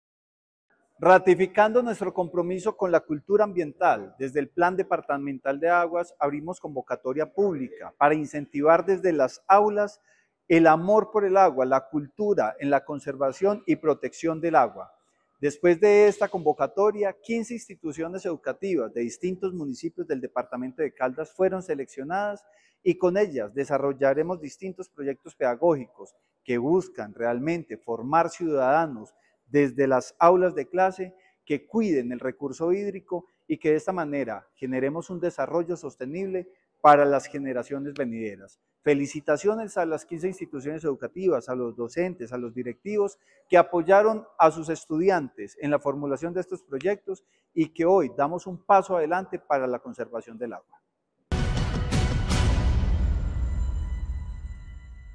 Francisco Javier Vélez Quiroga, secretario de Vivienda y Territorio de Caldas